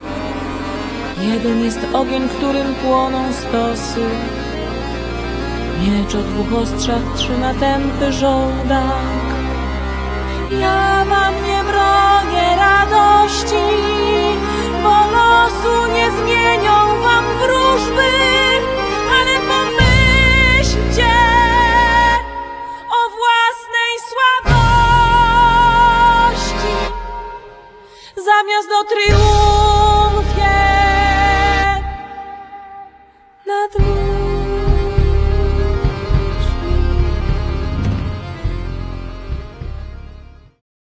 śpiew
kontrabas
pekusja
akordeon